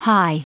Prononciation du mot high en anglais (fichier audio)
Prononciation du mot : high